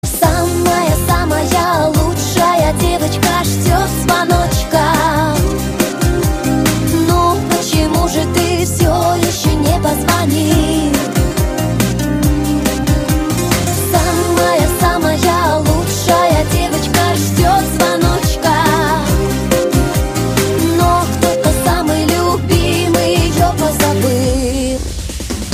• Качество: 128, Stereo
поп
женский вокал
русская попса